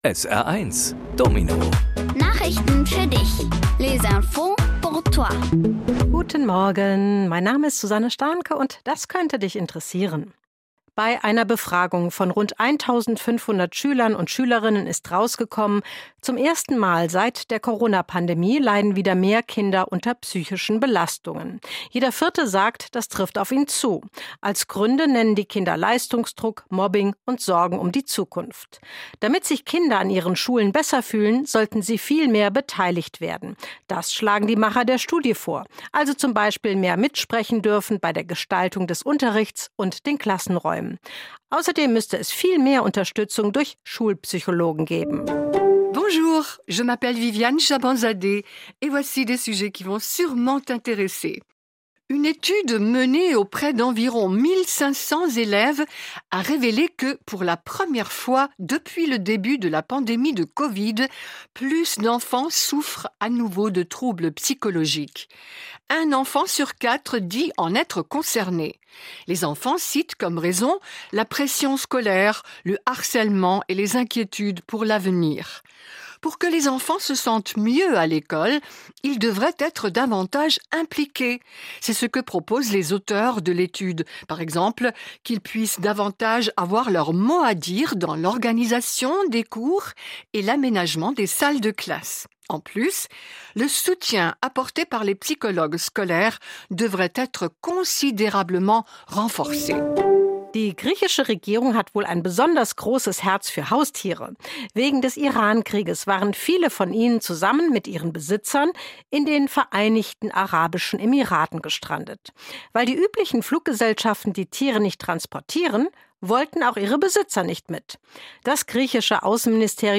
Kindgerechte Nachrichten auf Deutsch und Französisch:- Kinder mehr belastet- Griechen fliegen mit Haustieren- Finnen sind am glücklichsten- Possum zwischen Kuscheltieren